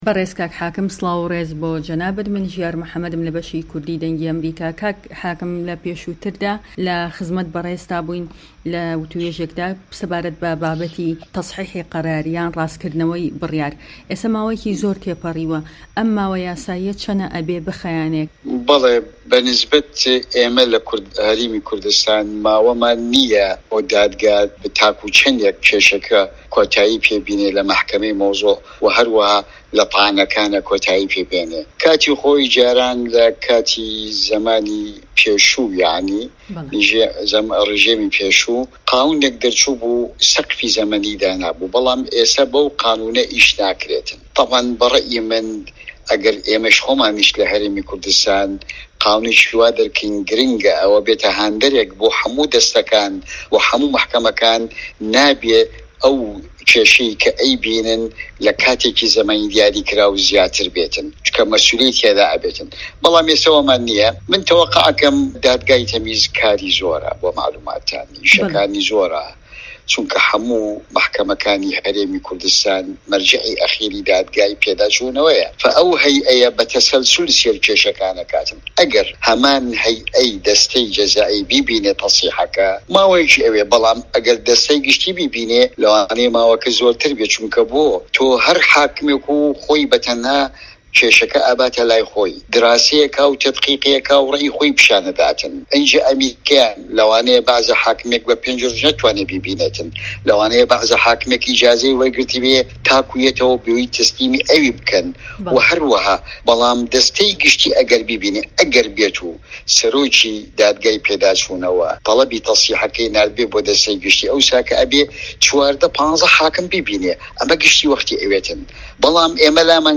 دەقی وتووێژەکەی